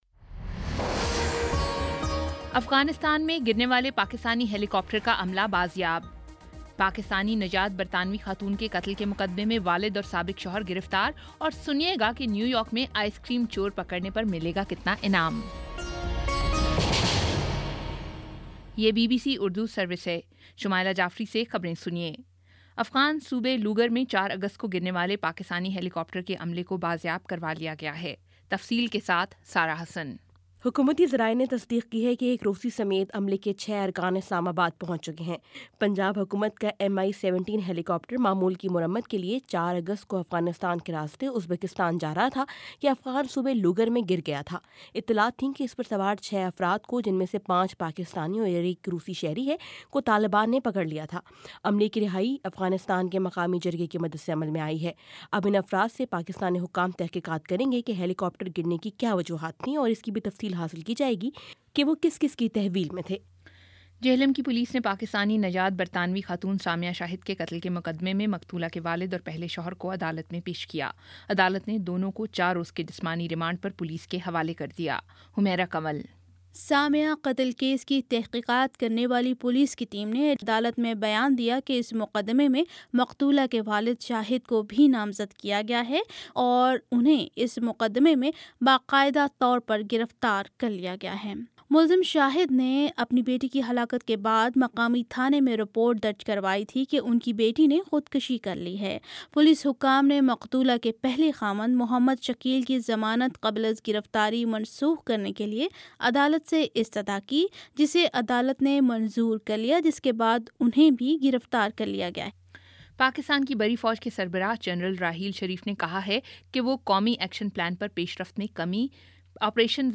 اگست 13 : شام سات بجے کا نیوز بُلیٹن